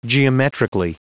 Prononciation du mot geometrically en anglais (fichier audio)
Prononciation du mot : geometrically